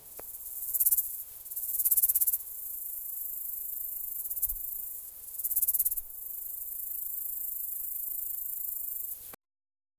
Insect Sound Effects - Free AI Generator & Downloads
one-metal-fly-the-insect-mjd2lwsu.wav